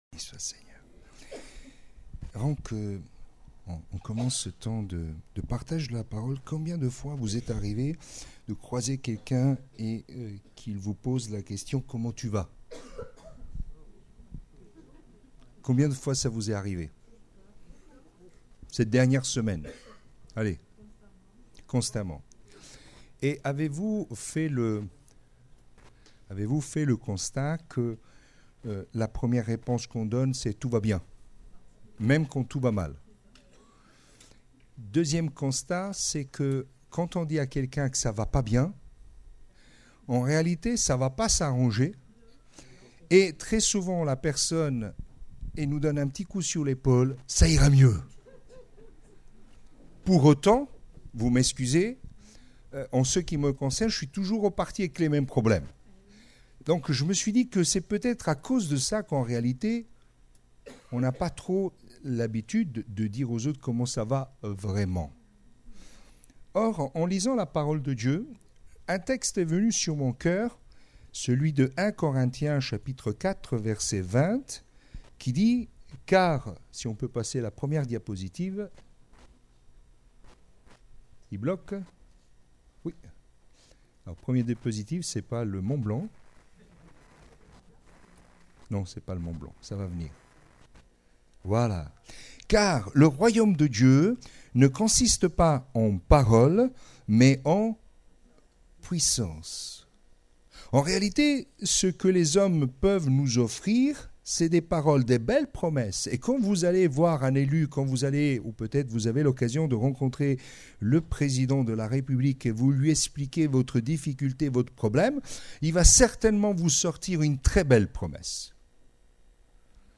Type De Service: Etude